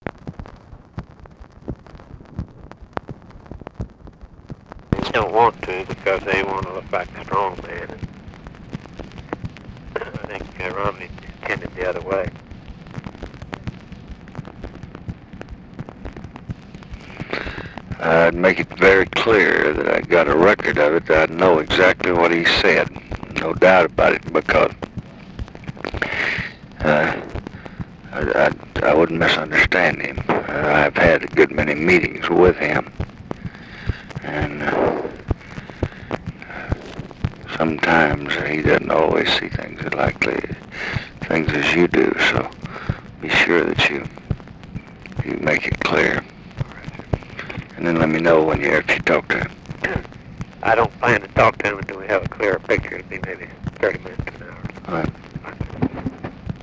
Conversation with RAMSEY CLARK, July 24, 1967
Secret White House Tapes